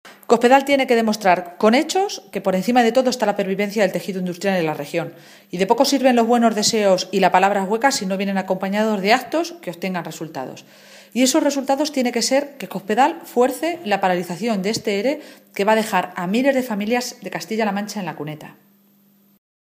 Cristina Maestre, Vicesecretaria y portavoz del PSOE de Castilla-La Mancha
Cortes de audio de la rueda de prensa